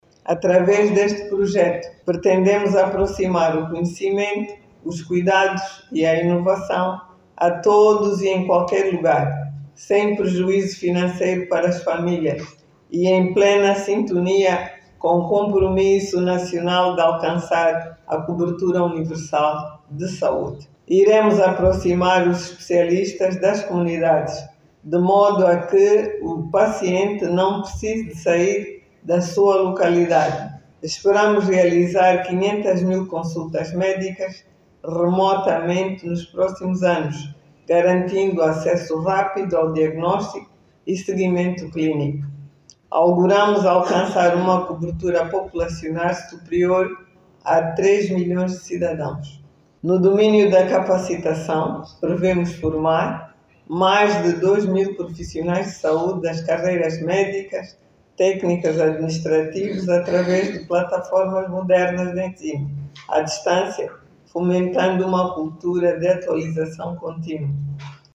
Na ocasião, a Ministra da Saúde, Sílvia Lutukuta, destacou que o projecto vai permitir aproximar os serviços aos cidadãos, promover formação contínua e garantir cobertura universal de saúde.
SILVIA-LUTUCUTA-13-HRS.mp3